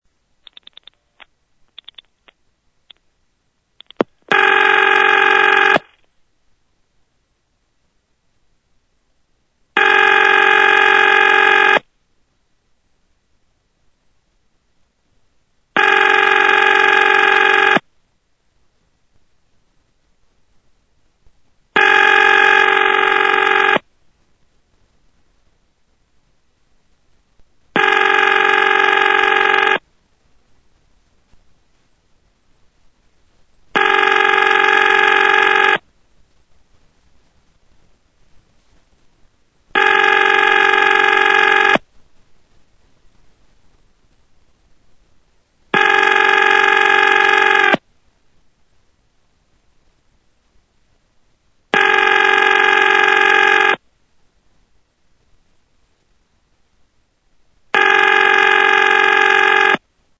These were made over the Collector’s network  (C-Net) using an Analog Telephone Adapter (ATA) via the Internet.
Ring (October 2024)